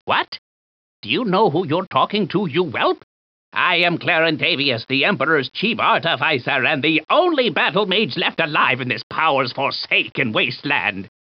Voice line of Reply 1 from Clarentavious in Battlespire.